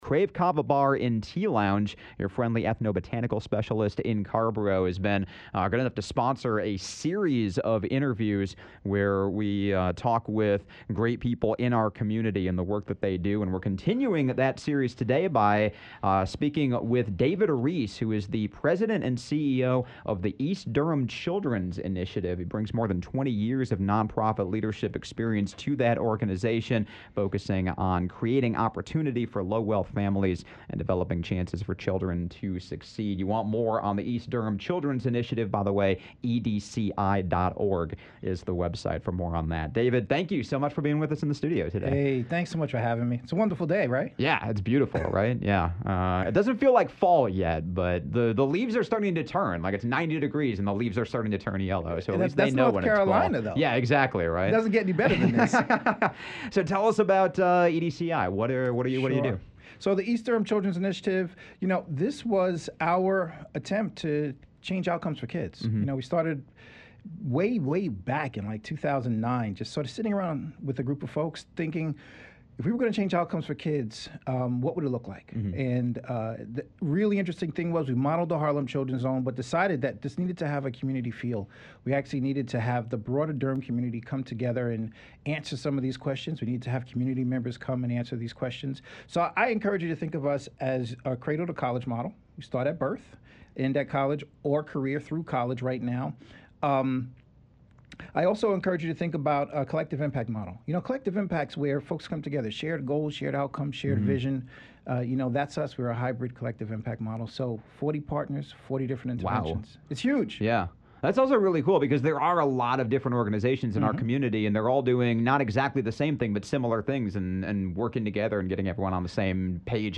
Conversation sponsored by Krave Kava Bar and Tea Lounge in Carrboro.